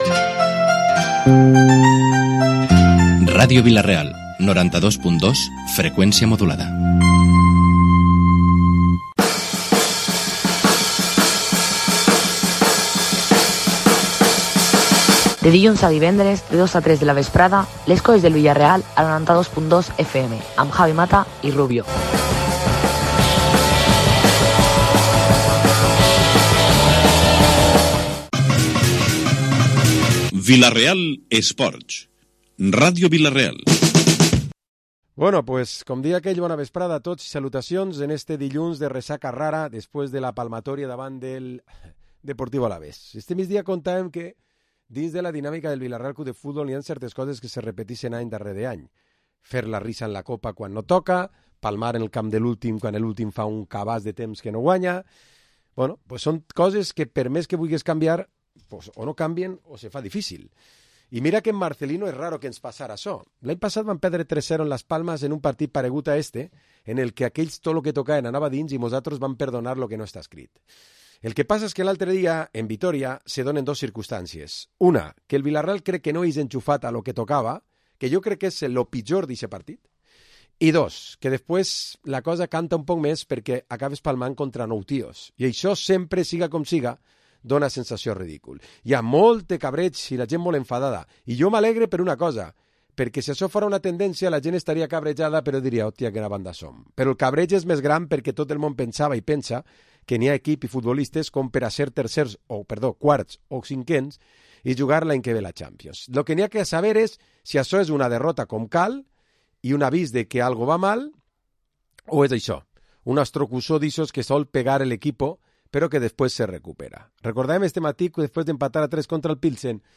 Programa Esports dilluns tertúlia 10 de març